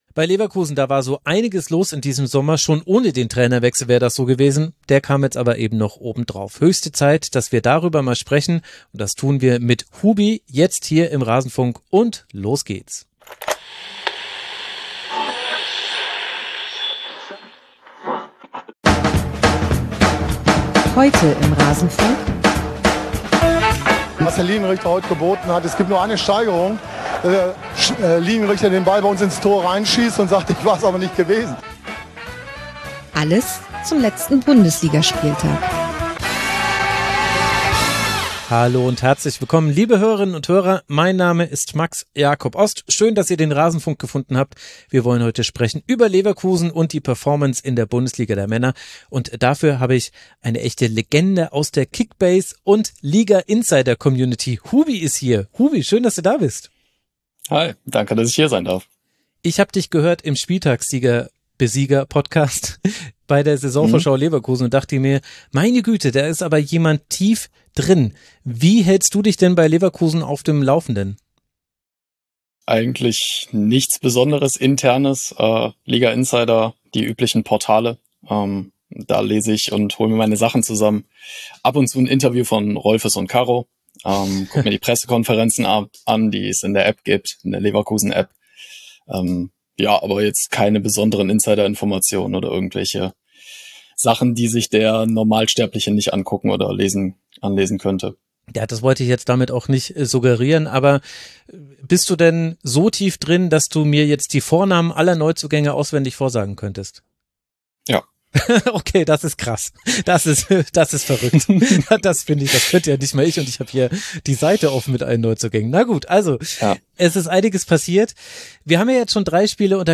Neben dem Geschehen auf dem Platz besprechen wir mit unseren Gästen auch das, was daneben passiert.
… continue reading 518 Episoden # Bundesliga # Spieltagsanalyse # Fußballtalk # Sport News # Nachrichten # Rasenfunk # Fußball # Sport # 1 Bundesliga # 1.